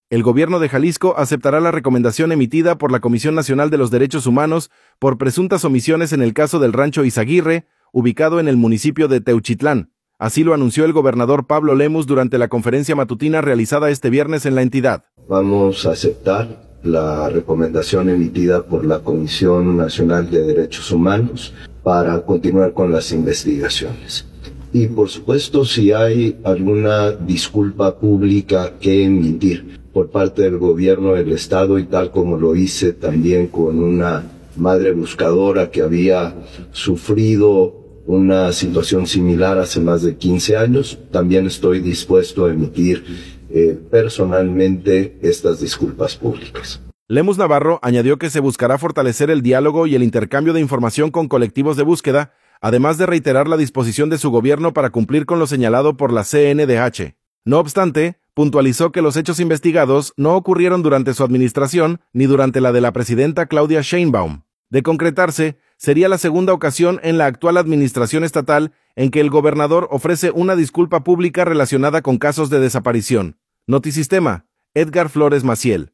El gobierno de Jalisco aceptará la recomendación emitida por la Comisión Nacional de los Derechos Humanos por presuntas omisiones en el caso del Rancho Izaguirre, ubicado en el municipio de Teuchitlán. Así lo anunció el gobernador Pablo Lemus Navarro durante la conferencia matutina realizada este viernes en la entidad.